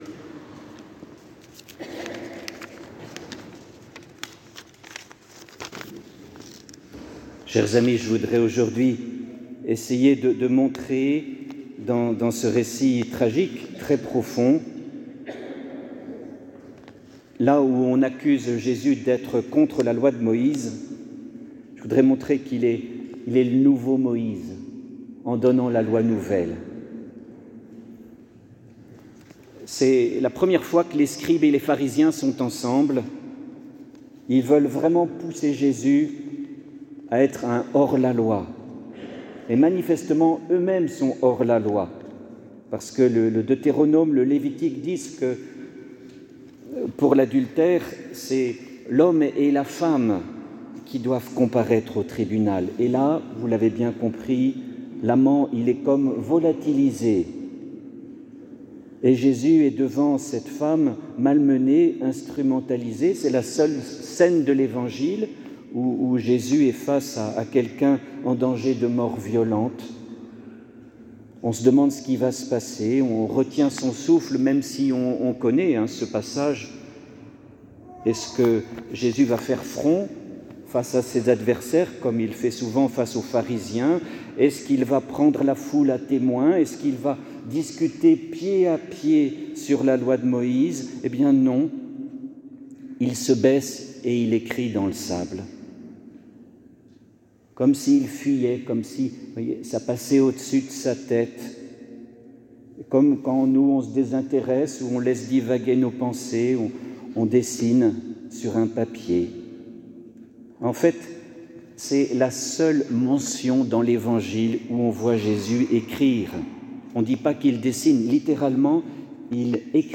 Eglise Saint Ignace
Homelie-la-femme-adultere-online-audio-converter.com_.mp3